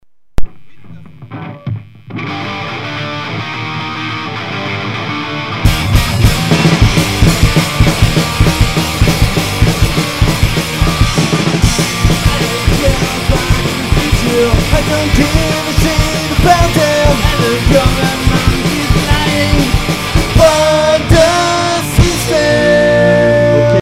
Live au Biplan